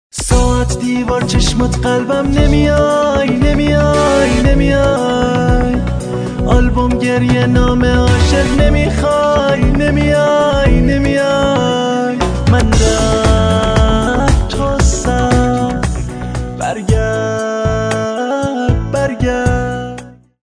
رینگتون احساسی و باکلام